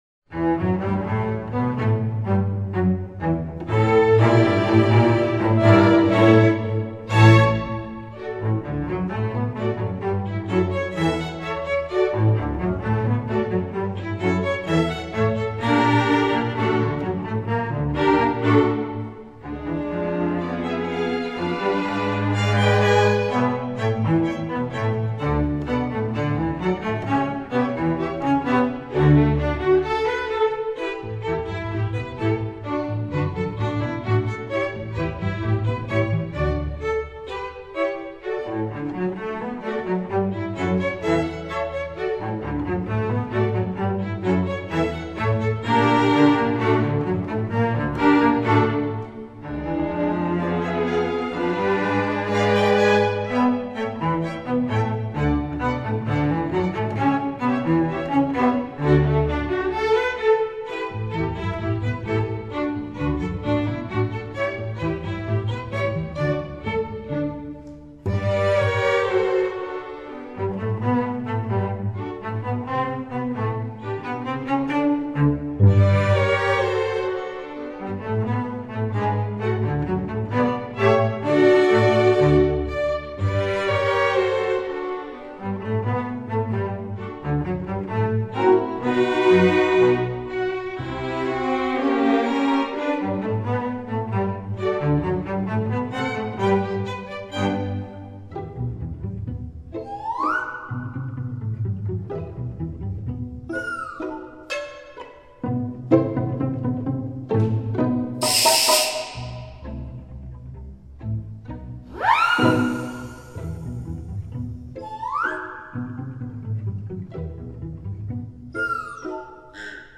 Instrumentation: string orchestra
instructional, children